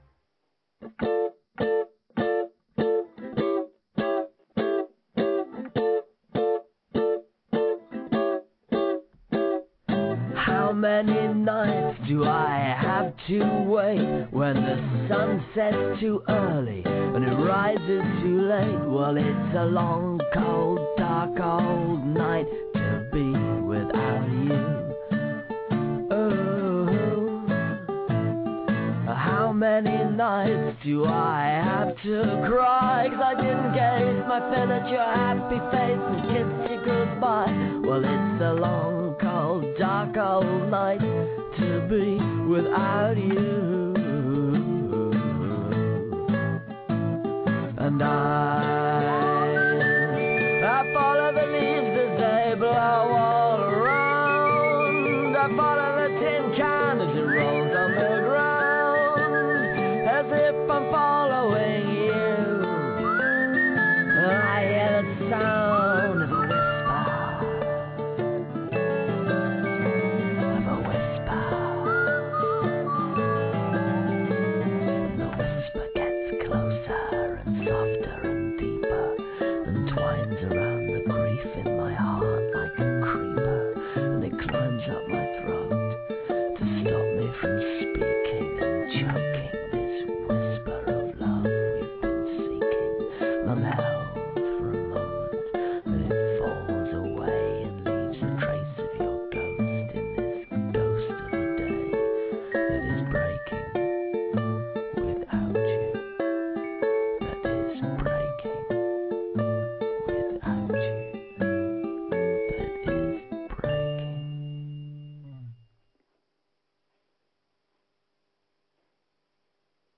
Flipron Interview